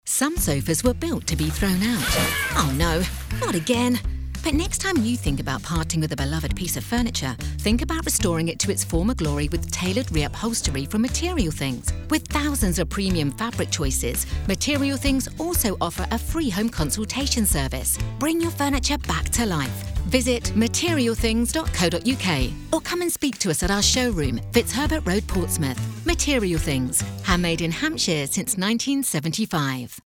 Female
English (British)
My voice has been described as friendly, rich, warm, relatable, and approachable.
My voice has been used for radio ads for a warm, friendly, and welcoming tone.
Radio Commercials
Words that describe my voice are Relatable, Rich, Approachable.